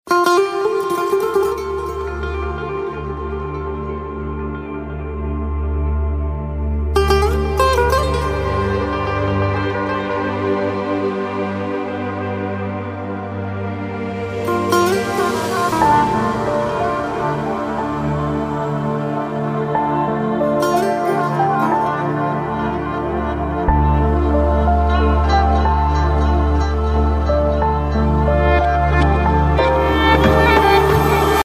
رینگتون عاشقانه و بی کلام